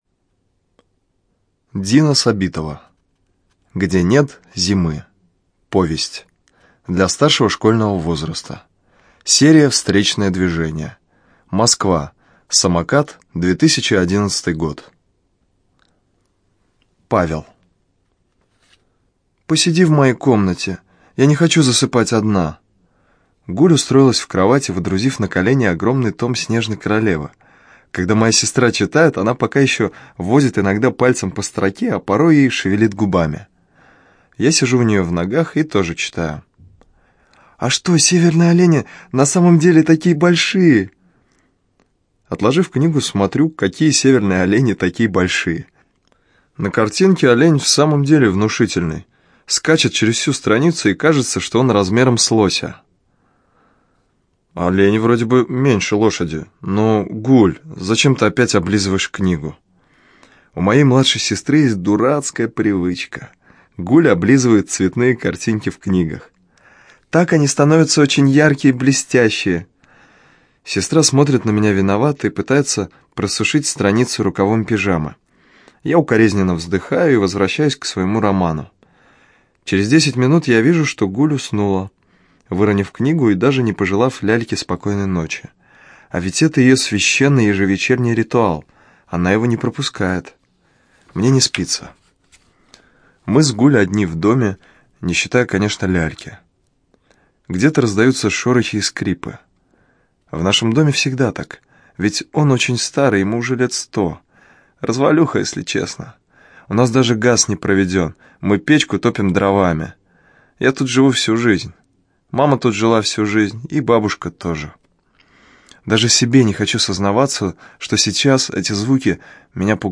ЖанрДетская литература, Современная проза
Студия звукозаписиЛогосвос